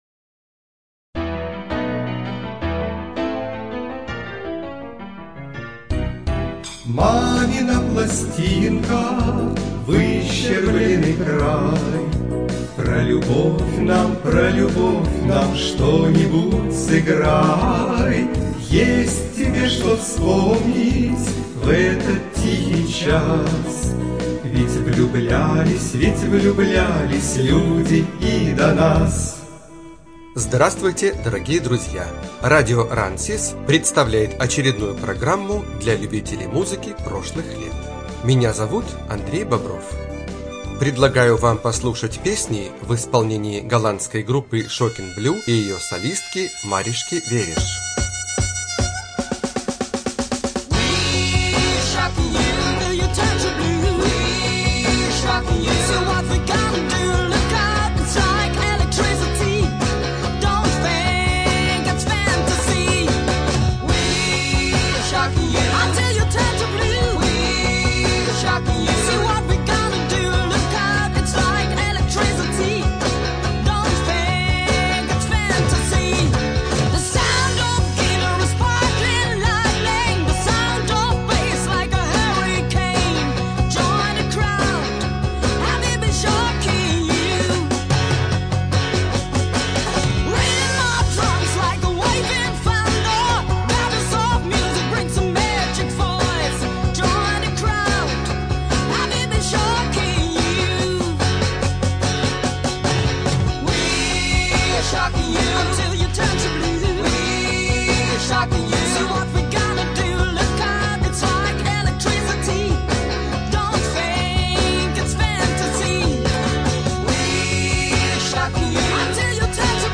Студия звукозаписиРадио Рансис